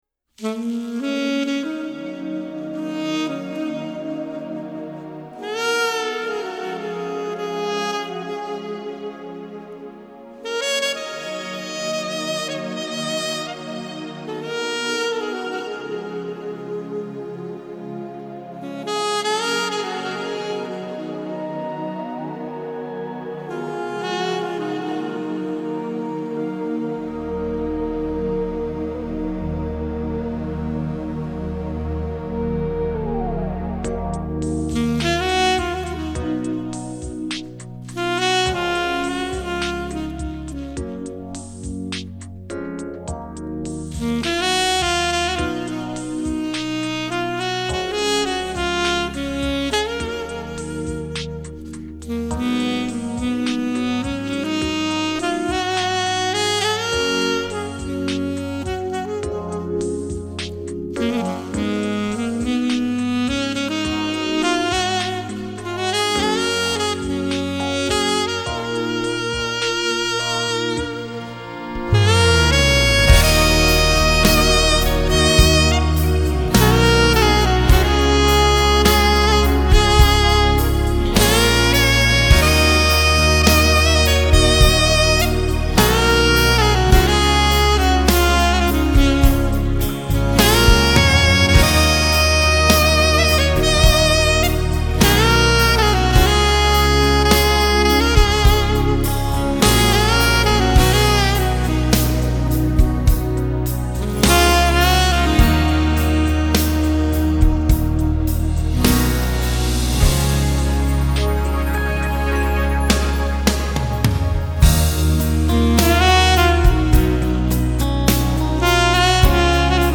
Прям дисторшн чувствуется на атаке, я думал кубы клюкаются, ан нет.